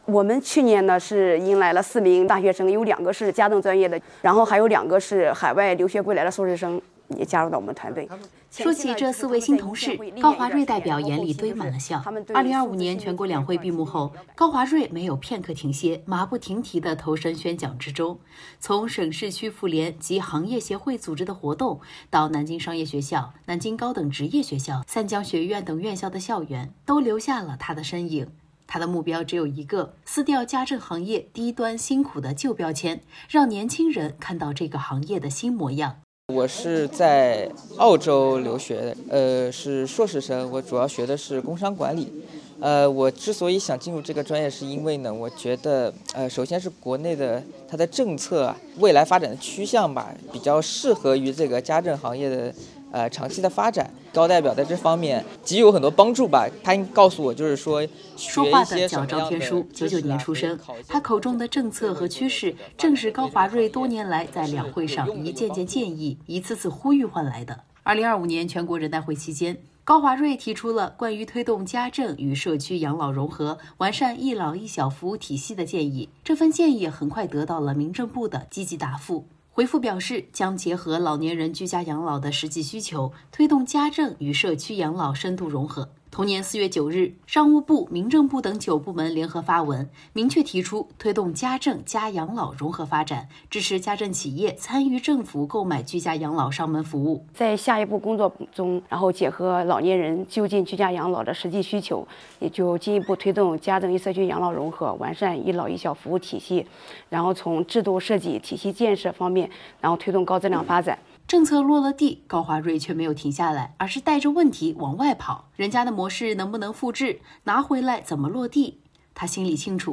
她把宣讲台搬进大学校园，用真诚打破“低端”偏见，吸引高学历人才扎根家政行业；她步履不停地跨省调研，为行业转型寻找可行路径……从发现问题到推动解决，高华瑞始终贴着地面行走，为的就是把基层呼声带上来，把政策红利落下去。2月27日的代表委员《履职一年间》，我们去和高华瑞代表聊一聊。